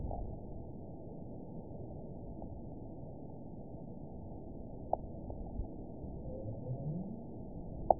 event 917759 date 04/15/23 time 05:47:18 GMT (2 years, 1 month ago) score 9.16 location TSS-AB01 detected by nrw target species NRW annotations +NRW Spectrogram: Frequency (kHz) vs. Time (s) audio not available .wav